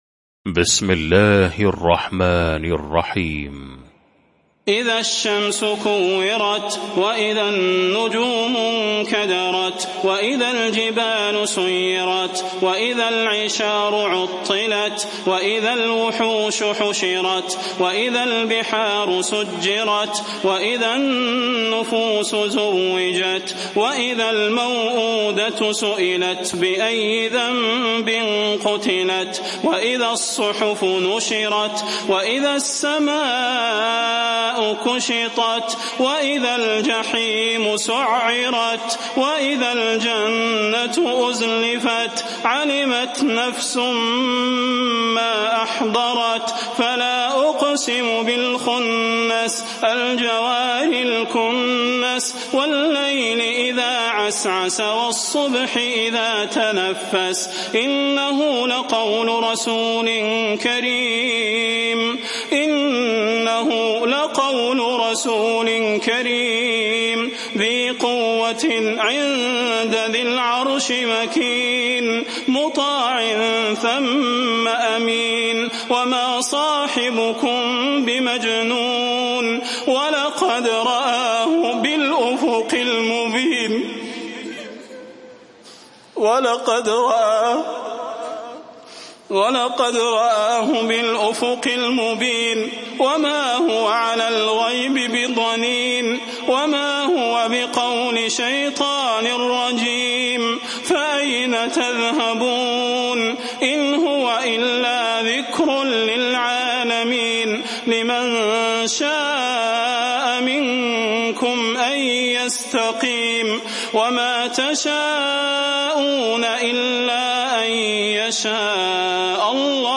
المكان: المسجد النبوي الشيخ: فضيلة الشيخ د. صلاح بن محمد البدير فضيلة الشيخ د. صلاح بن محمد البدير التكوير